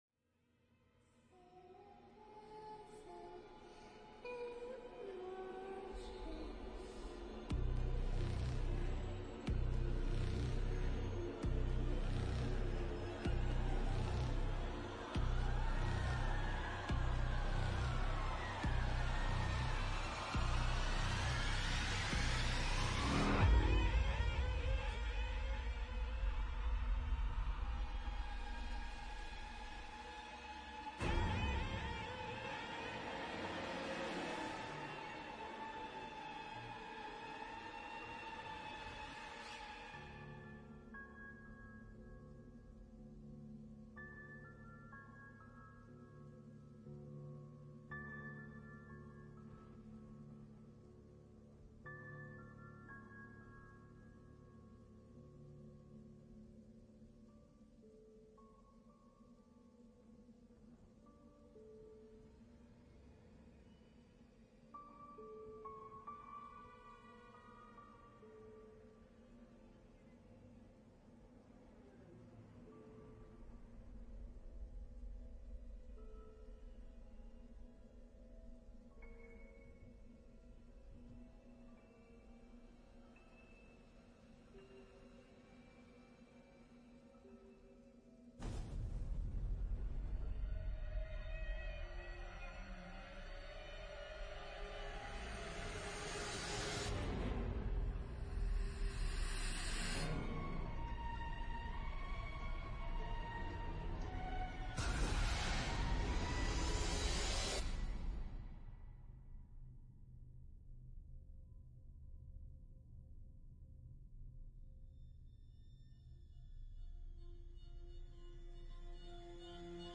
background_music_halloween.mp3